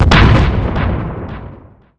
use_blast.wav